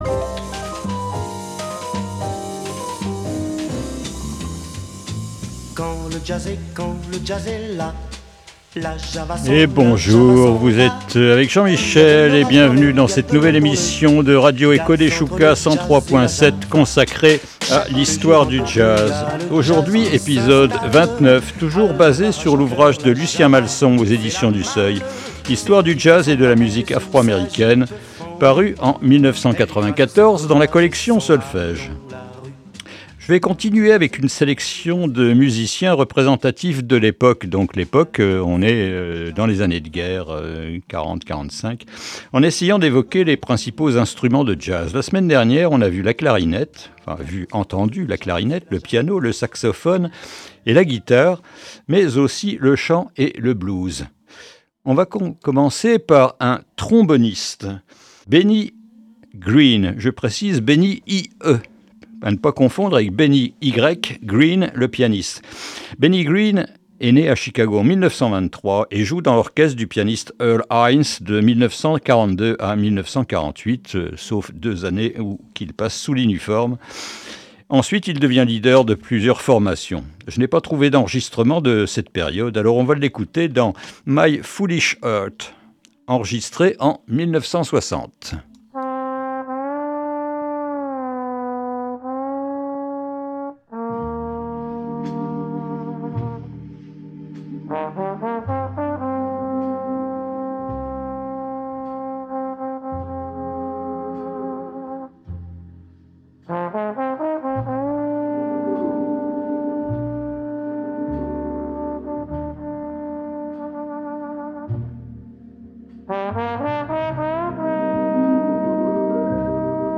Quand le jazz est là est une nouvelle émission consacrée à l’histoire du jazz.